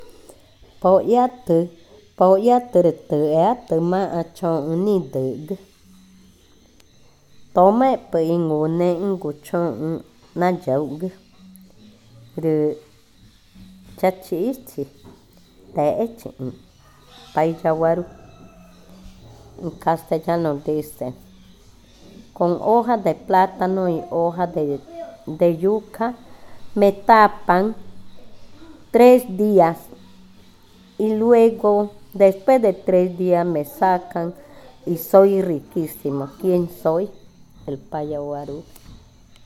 Adivinanza 19. Payavarú
Cushillococha